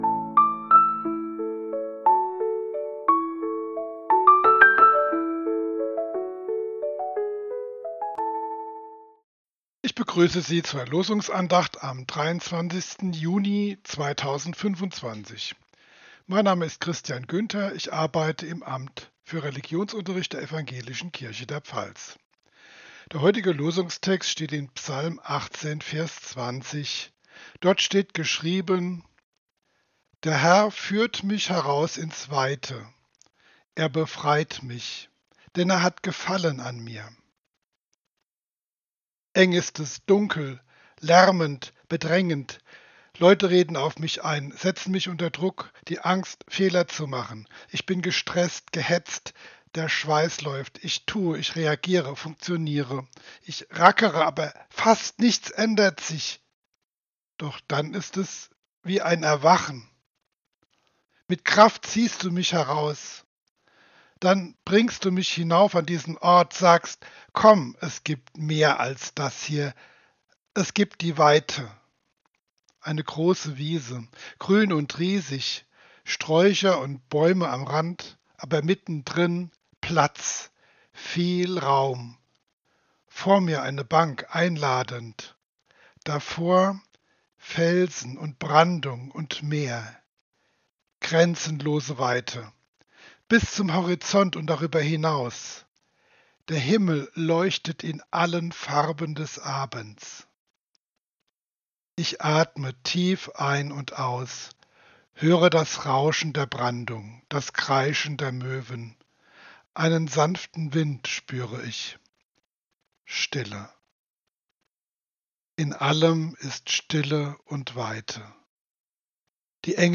Losungsandachten